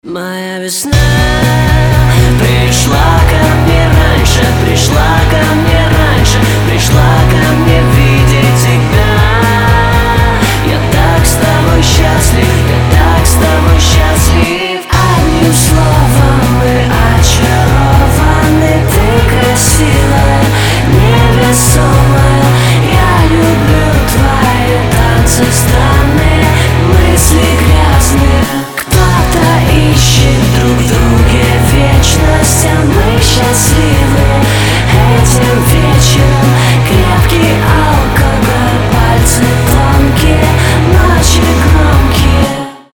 • Качество: 320, Stereo
мужской вокал
чувственные
романтичные
легкий рок